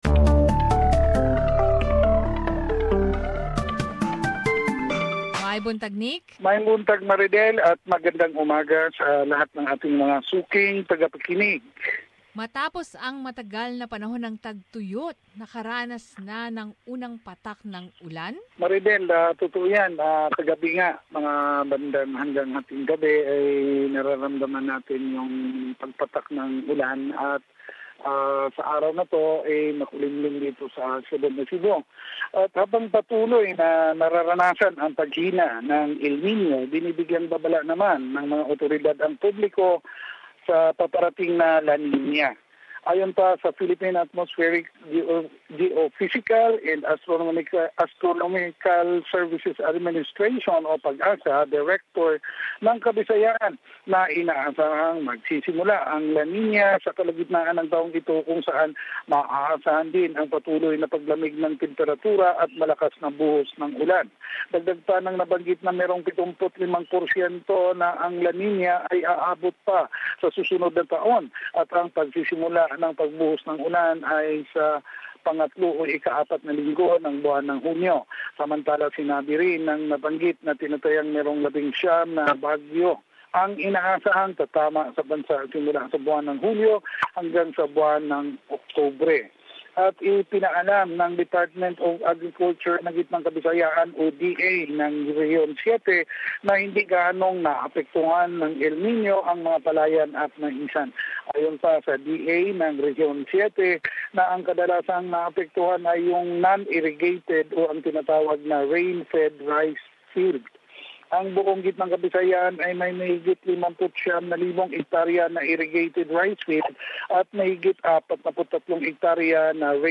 Balitang Bisayas.